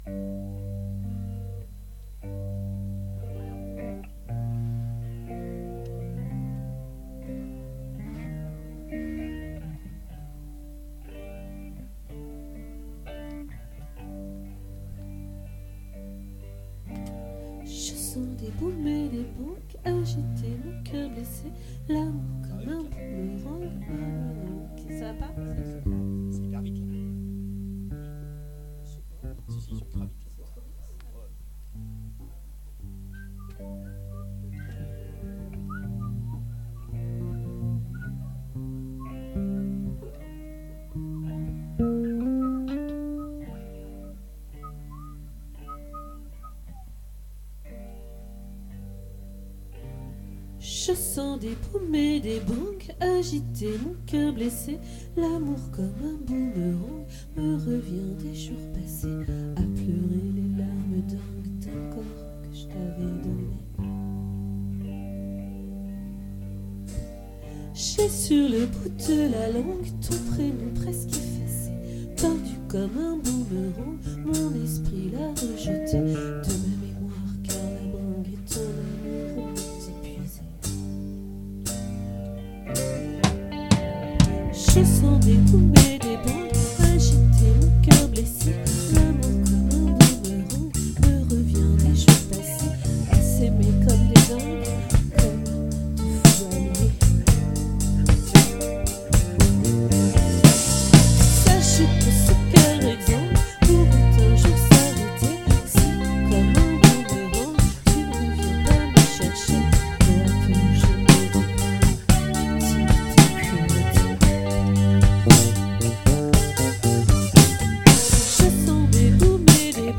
🏠 Accueil Repetitions Records_2023_04_19